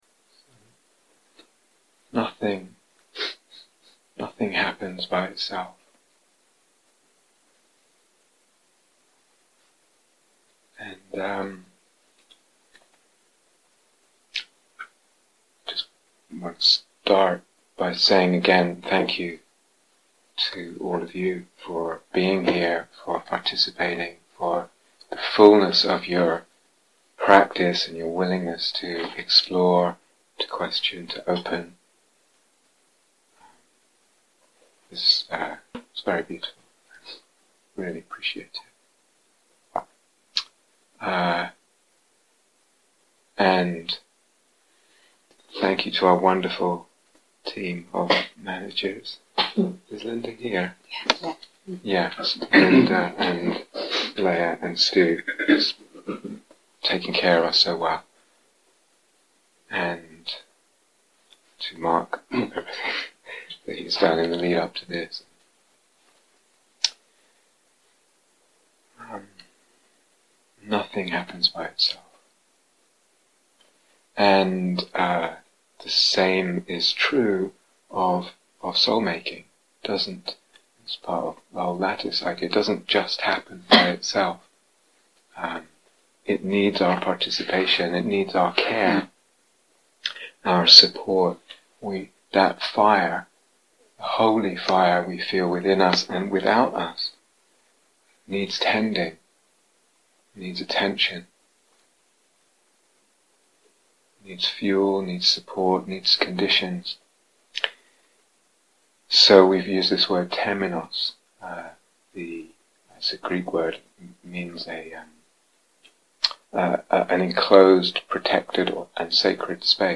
(Freely Given Retreats) The talks and exercises from this 'Tending the Holy Fire' retreat are intended for experienced practitioners who already have a working familiarity with this particular Soulmaking paradigm, as outlined, for example, in the following retreats: 'The Path of the Imaginal (Longer Course)'; 'Re-enchanting the Cosmos: The Poetry of Perception'; and 'Of Hermits and Lovers: The Alchemy of Desire'.